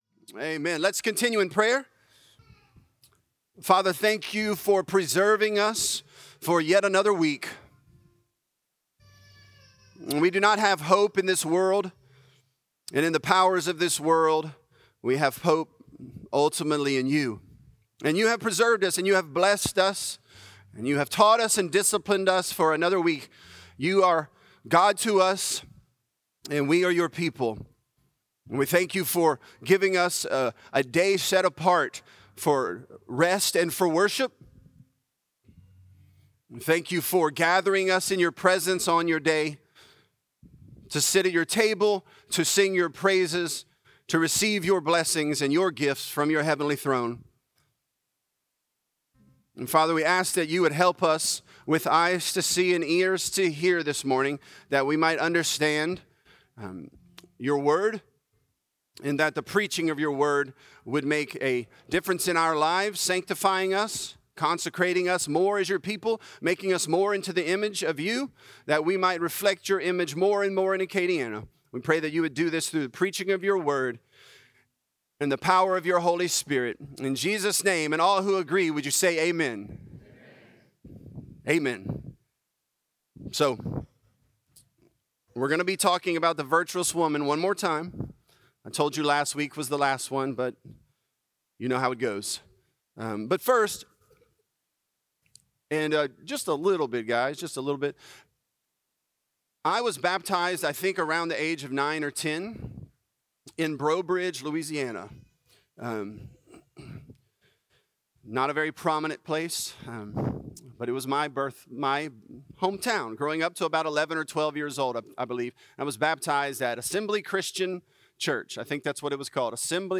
Virtuous: In the Gates | Lafayette - Sermon (Proverbs 31)